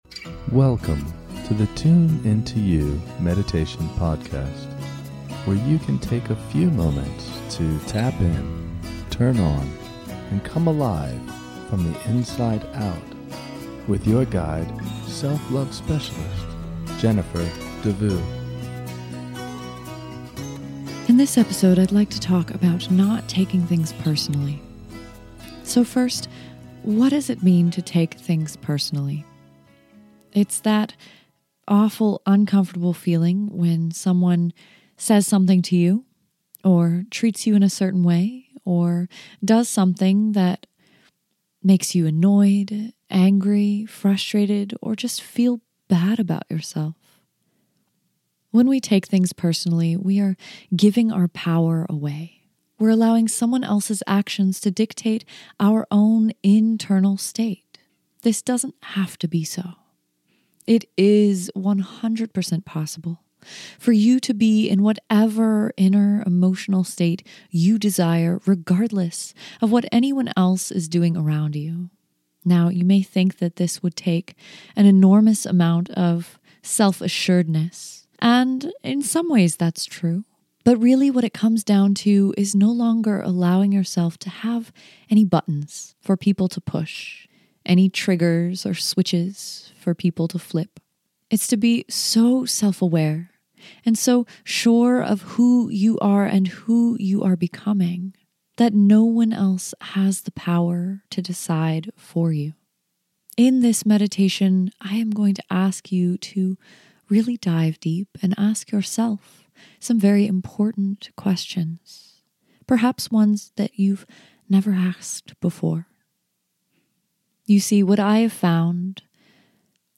In this short guided meditation, we will talk about why we take things personally and how to let it go.